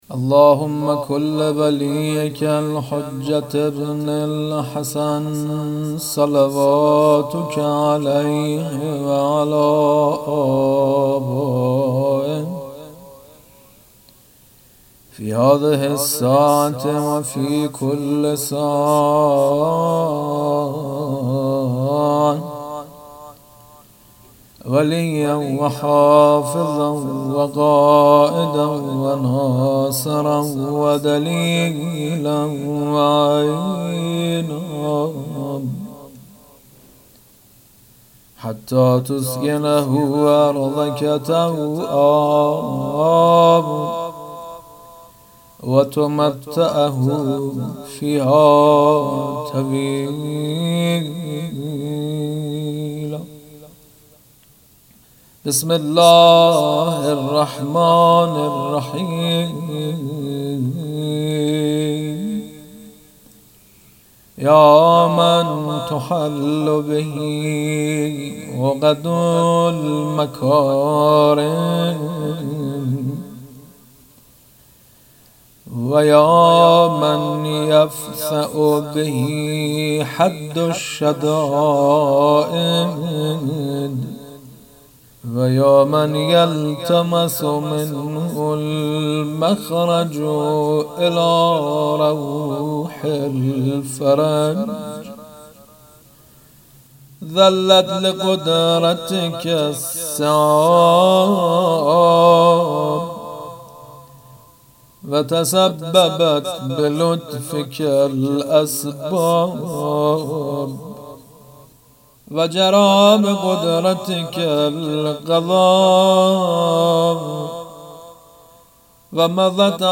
دعا
مناجات